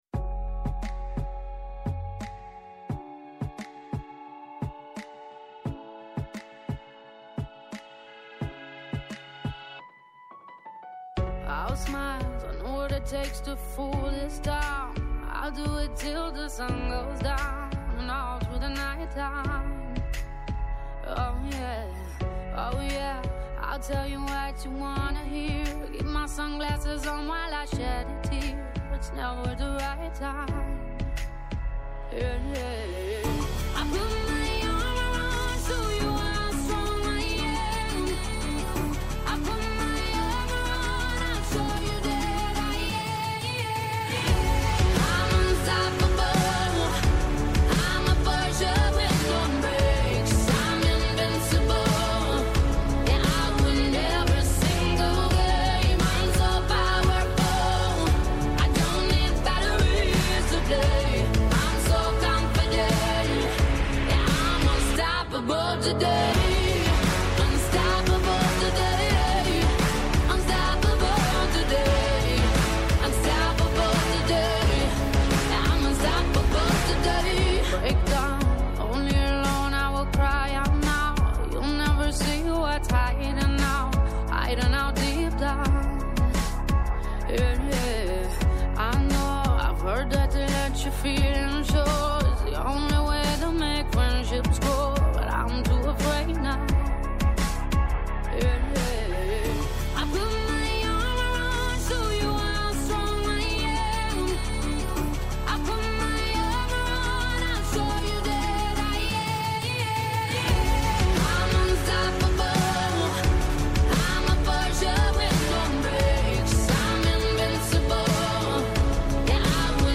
-Η Μαρία Καραμανώφ, Αντιπρόεδρος Συμβουλίου Επικρατείας